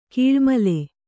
♪ kīḷmale